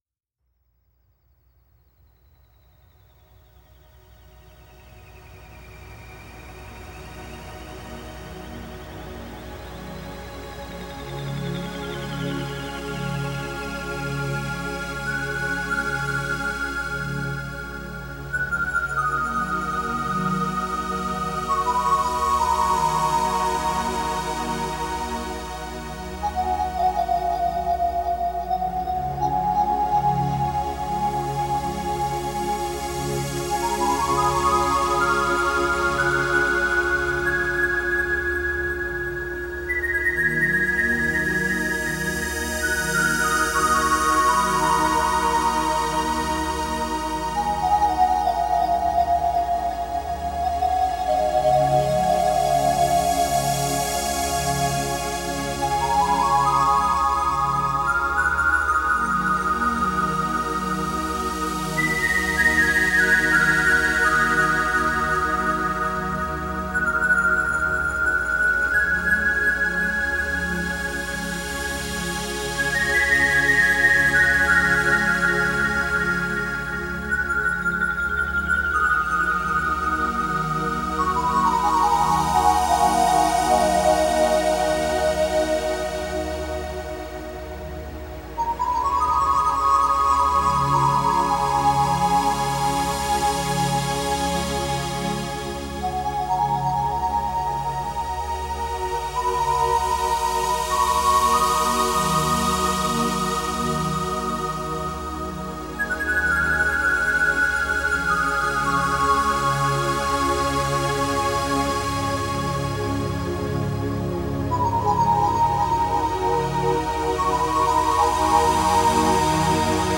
Ethereal sounds